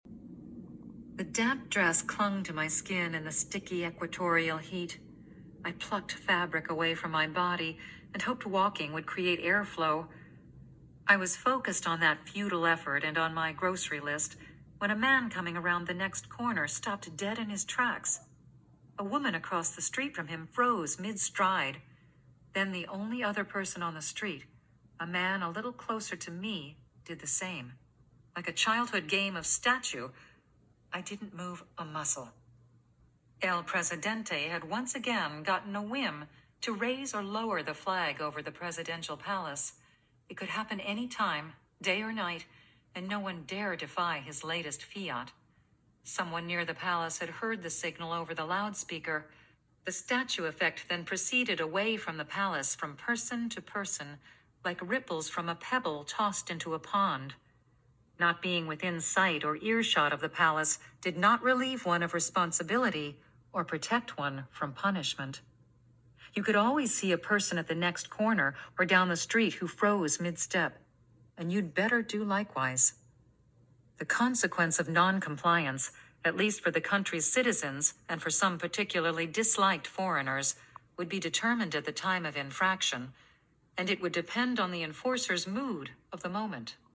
shortaudiobooksample.mp3